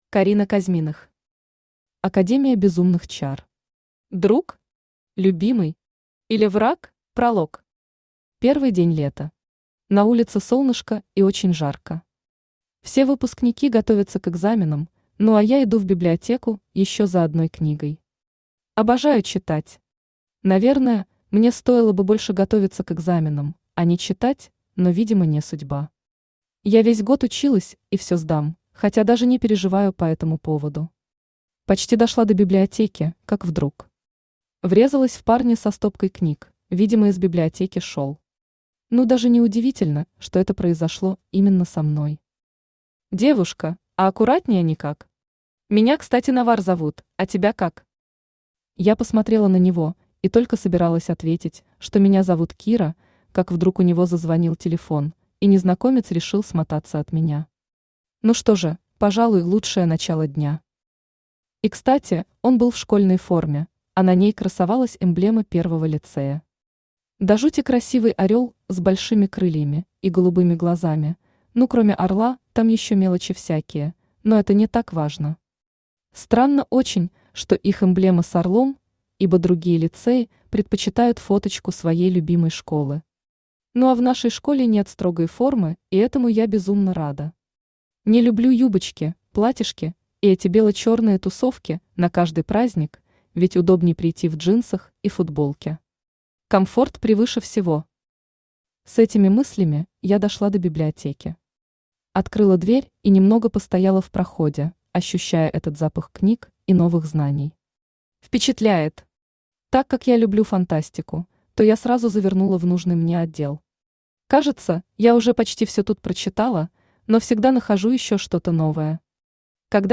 Аудиокнига Академия безумных чар. Друг? Любимый? Или враг? | Библиотека аудиокниг
Автор Карина Денисовна Козьминых Читает аудиокнигу Авточтец ЛитРес.